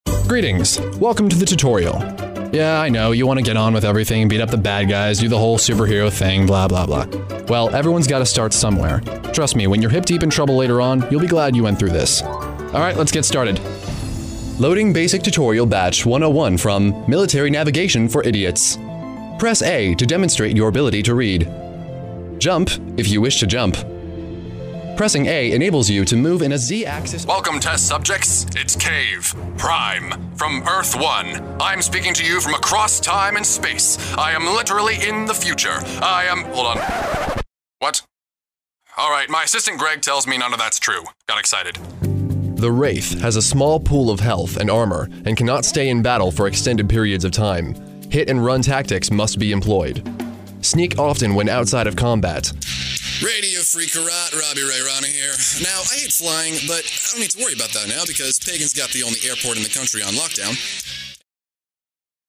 Male
Genuine youthful sound, but with range in more mature sounds as well.
Video Games
Tutorials / Tutorial Characters
Words that describe my voice are Youthful, Friendly, Conversational.